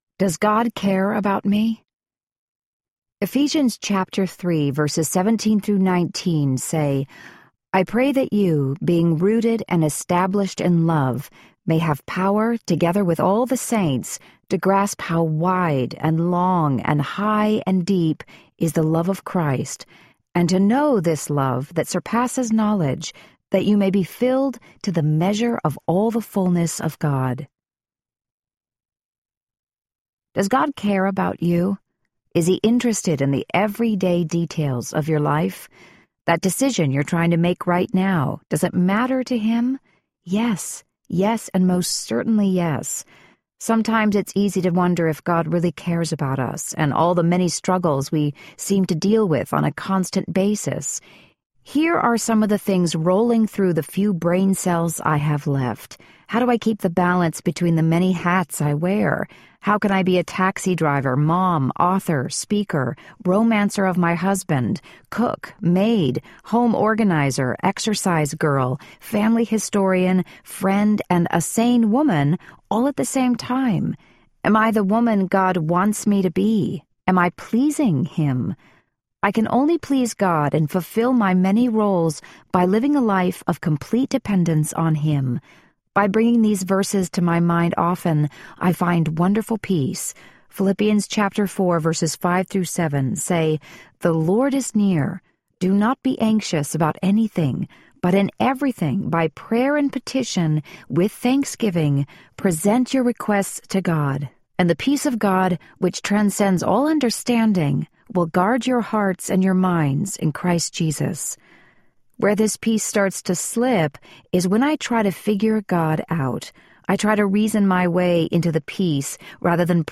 Am I Messing Up My Kids? Audiobook